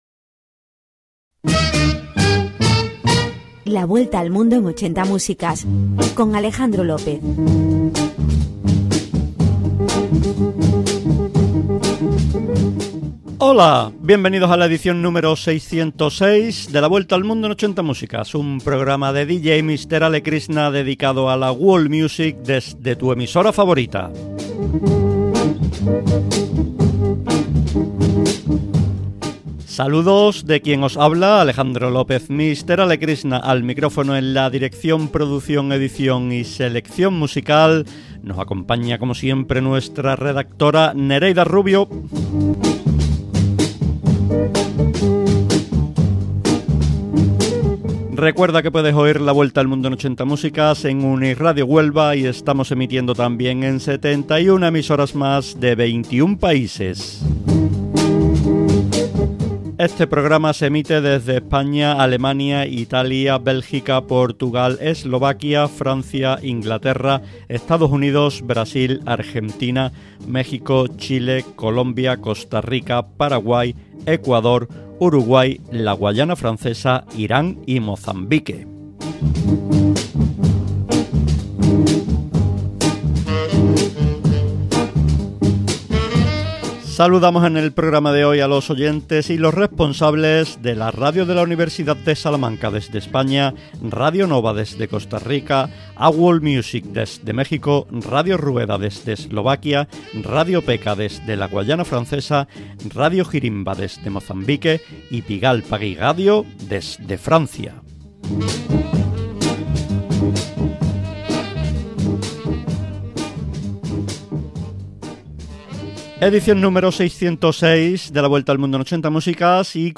Musical
Espai realitzat des d'UniRadio, la ràdio de la Universidad de Huelva.